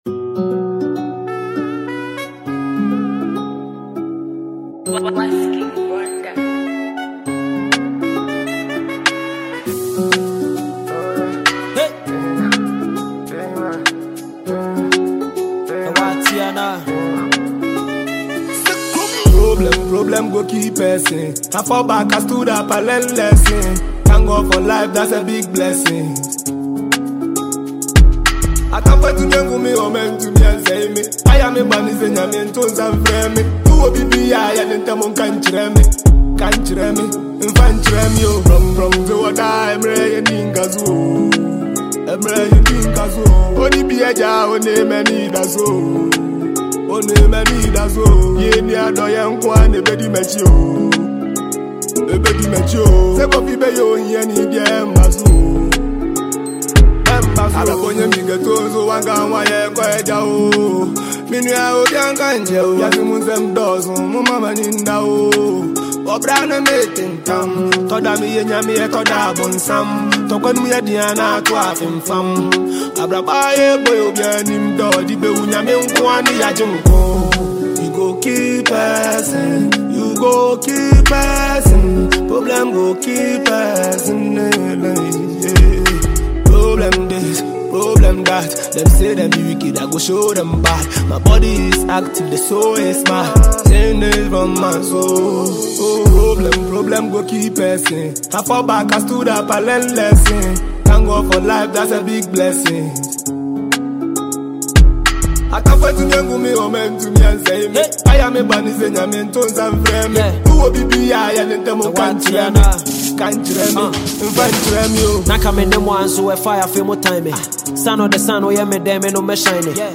rapper
Packed with punchlines, catchy flows, and street vibes